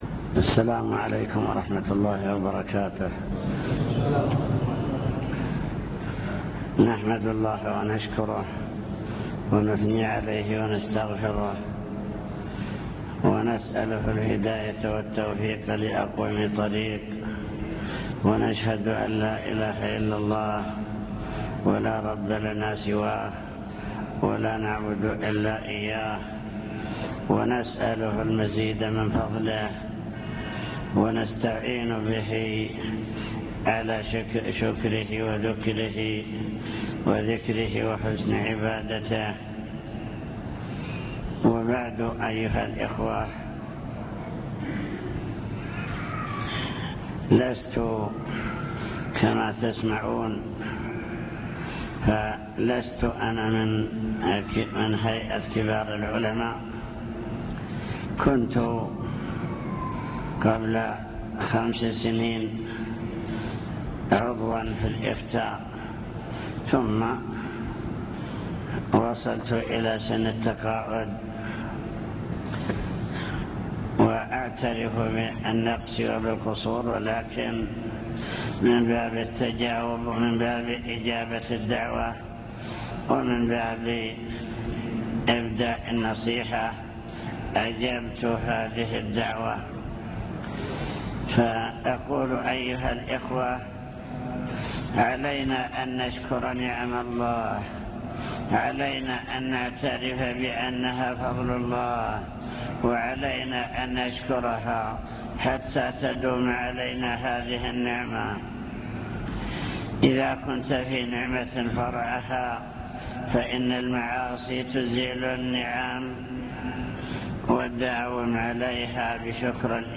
المكتبة الصوتية  تسجيلات - محاضرات ودروس  محاضرة بعنوان شكر النعم (1)